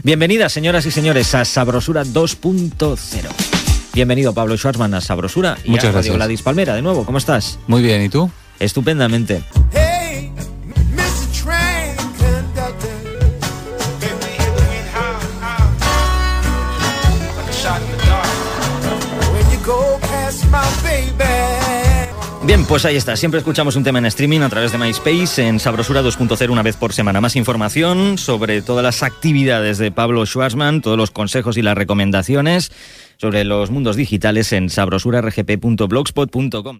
Llatina
Musical